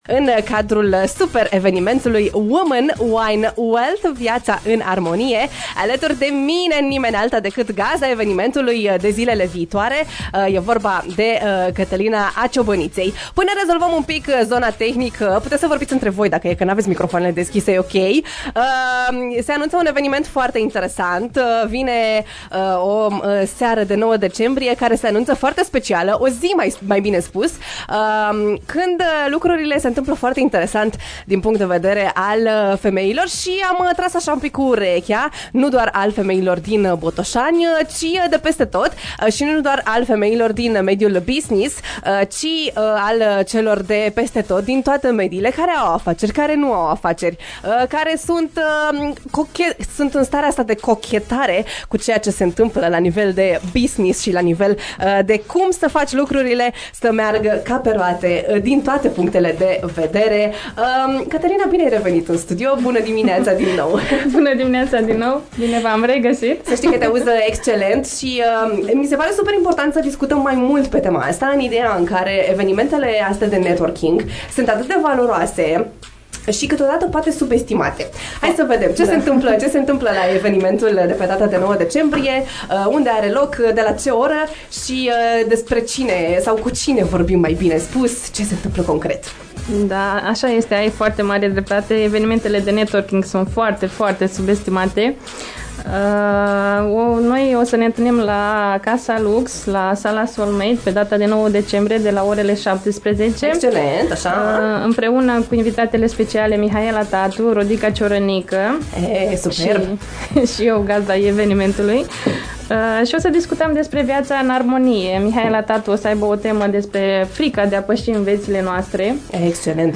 Live în AFTER MORNING despre Women, Wine & Wealth – viața în armonie